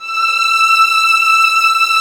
Index of /90_sSampleCDs/Roland L-CD702/VOL-1/STR_Vlns 6 mf-f/STR_Vls6 mf%f M